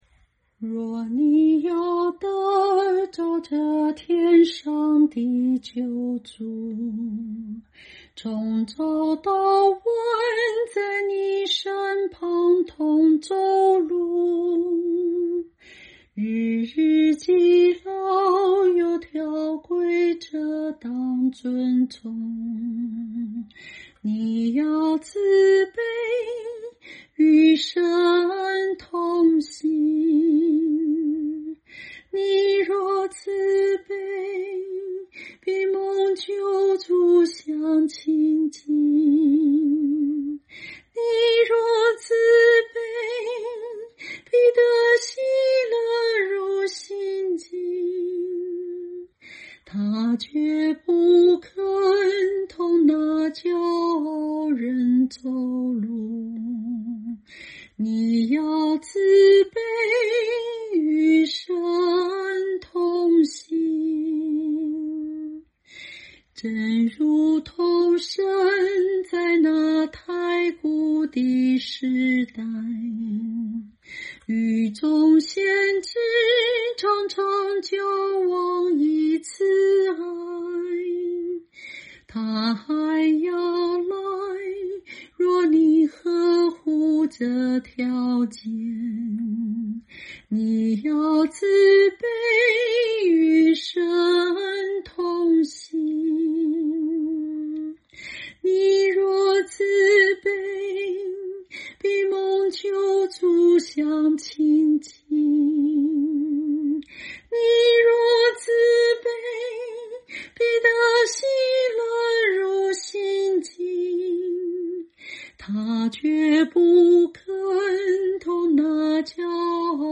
清唱    伴奏